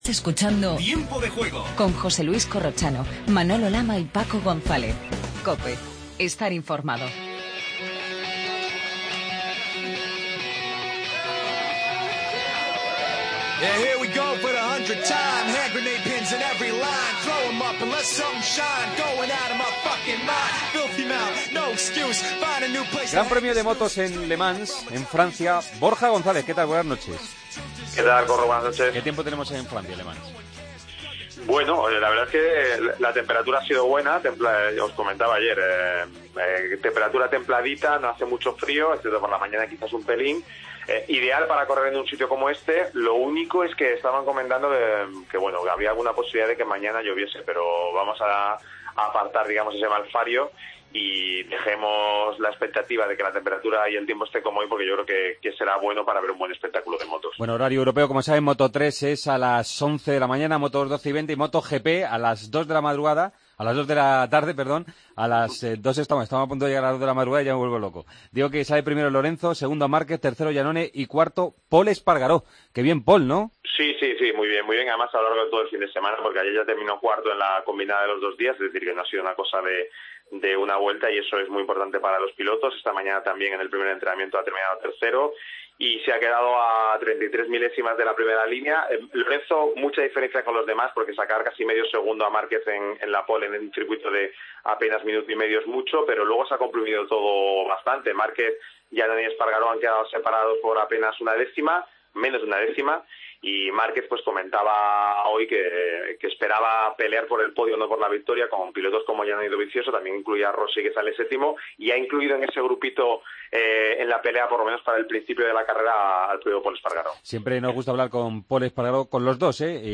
Redacción digital Madrid - Publicado el 08 may 2016, 02:50 - Actualizado 15 mar 2023, 18:02 1 min lectura Descargar Facebook Twitter Whatsapp Telegram Enviar por email Copiar enlace Última hora del GP de Le Mans de motociclismo. Entrevista a Pol Espargaró. Tenis con la eliminación de Nadal del Masters 1000 de Madrid.